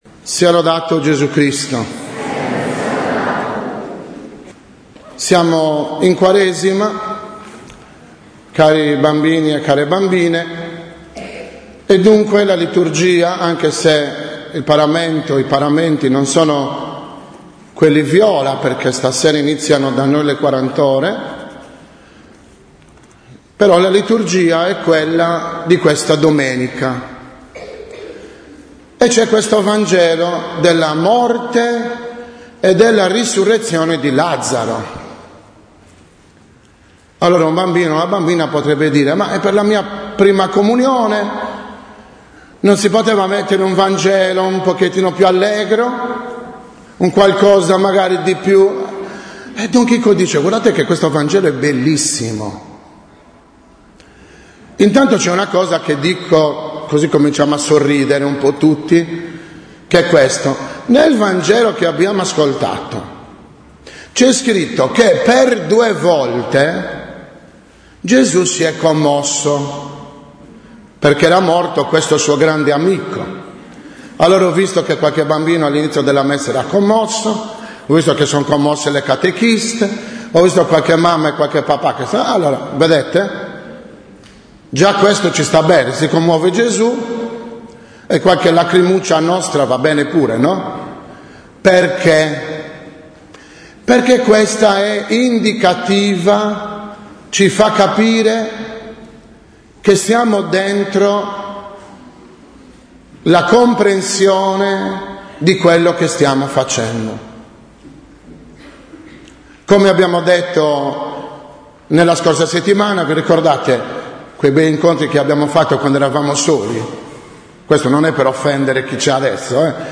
6.04.2014 – OMELIA DELLA CELEBRAZIONE DELLE PRIME COMUNIONI